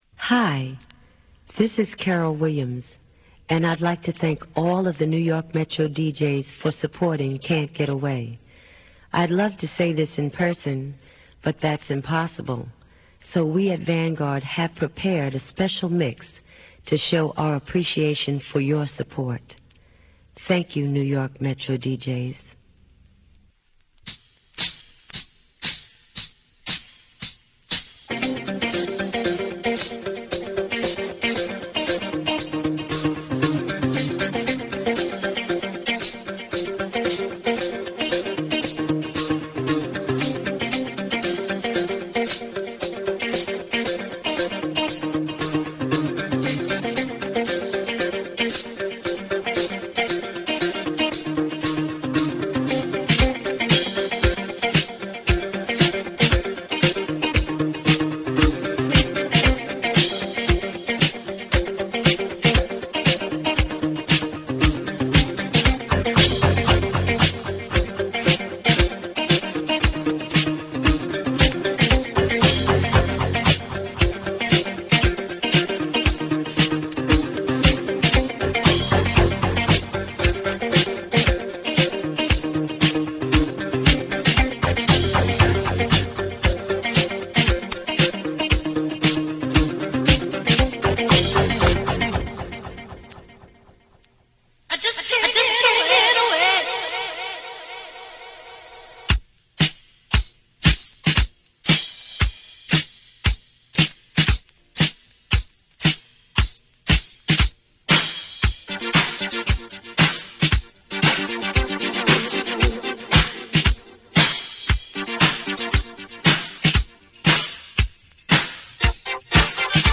special 10-inch Dub mix